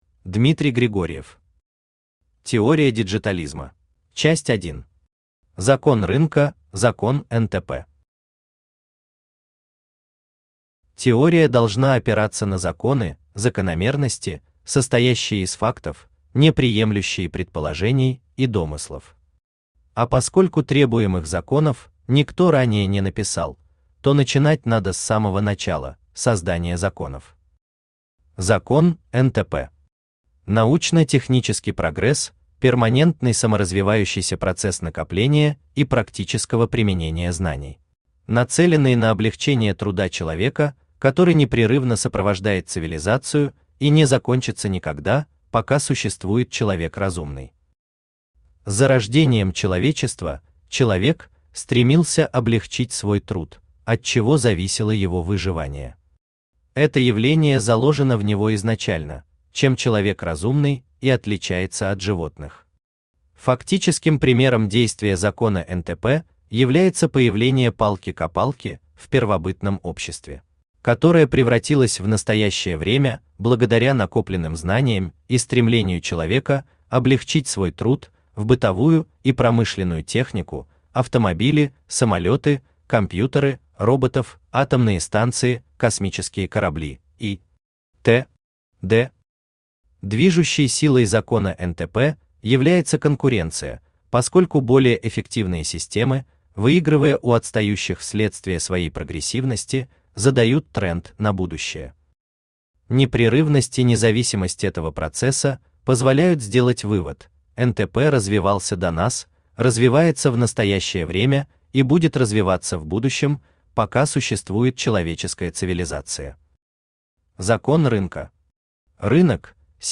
Aудиокнига Теория Диджитализма Автор Дмитрий Валерьевич Григорьев Читает аудиокнигу Авточтец ЛитРес.